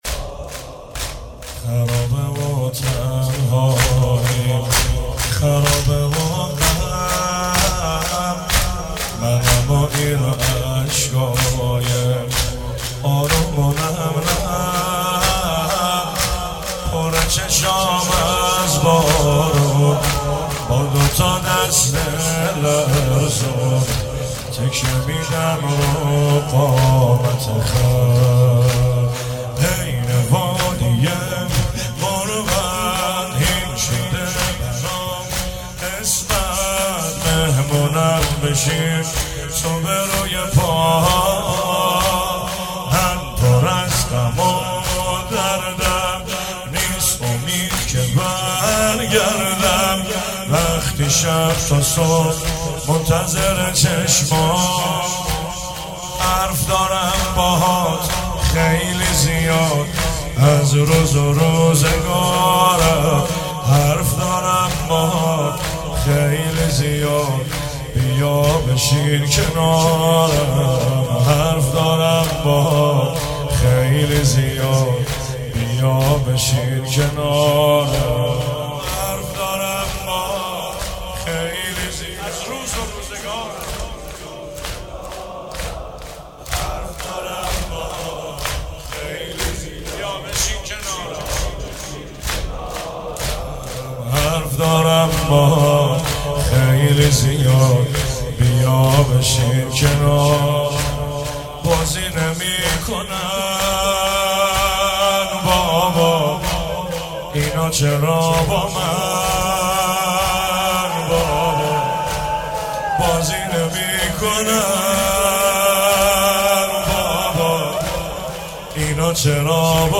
شب سوم رمضان 95، حاح محمدرضا طاهری
02 heiate alamdar mashhad alreza.mp3